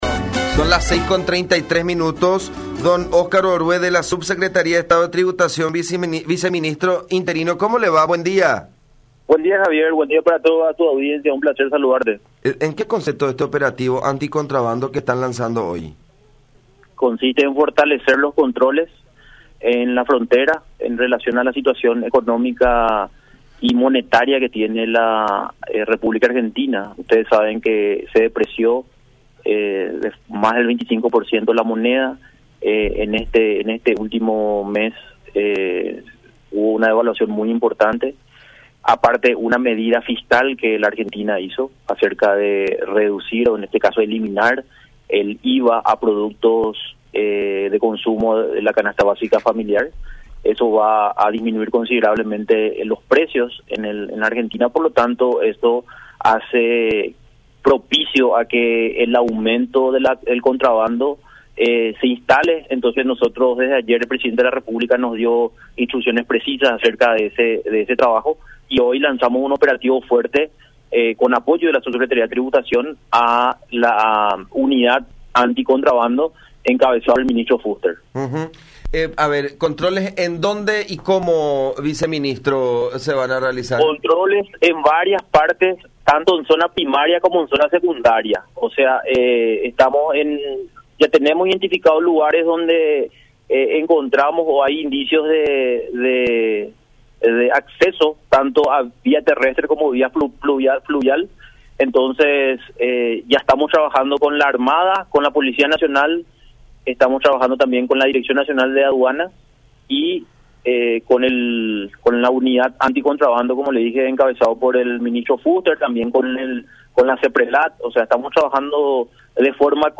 “Consiste en fortalecer los controles en las fronteras, en relación especialmente a la condición monetaria que tiene ahora Argentina”, explicó el viceministro interino de la SET, Óscar Orué, en contacto con La Unión, aseverando que la depreciación de la moneda del vecino país genere un ambiente “propicio” para el contrabando.